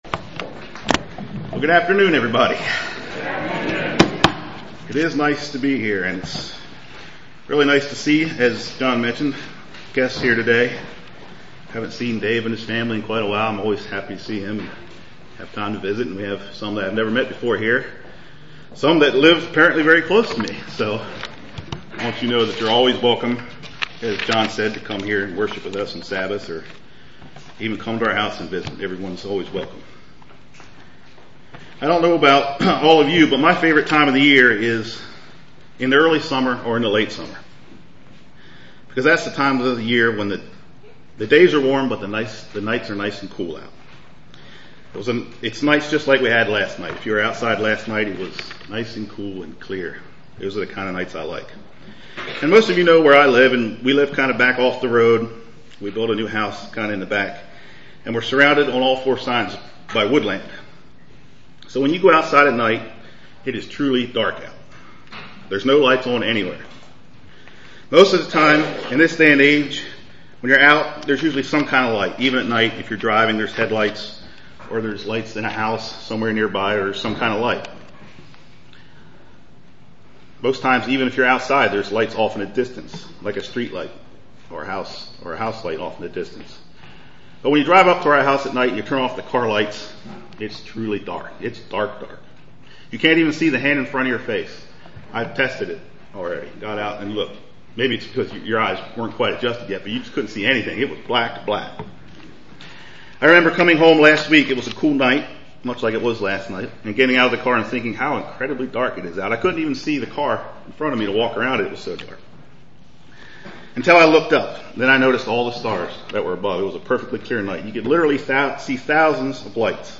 UCG Sermon Studying the bible?
Given in Lewistown, PA